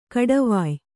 ♪ kaḍavāy